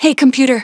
synthetic-wakewords
ovos-tts-plugin-deepponies_Naoto Shirogane_en.wav